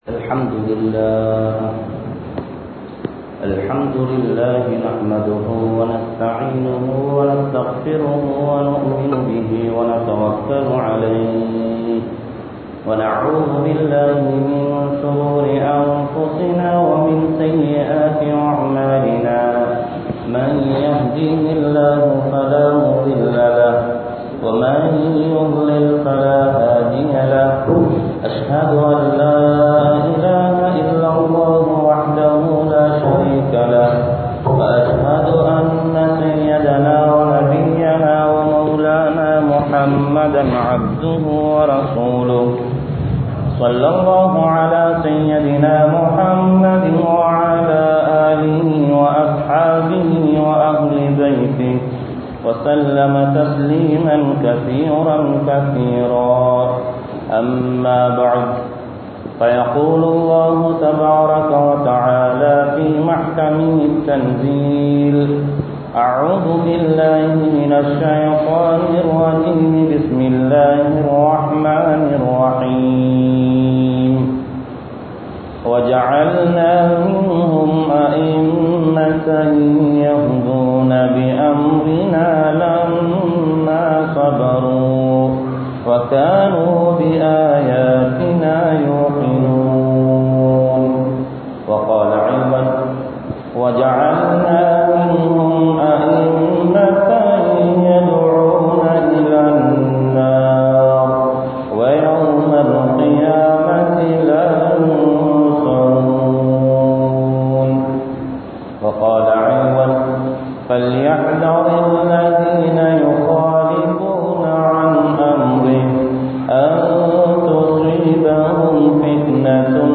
Madhhabin Avasiyam (மத்ஹபின் அவசியம்) | Audio Bayans | All Ceylon Muslim Youth Community | Addalaichenai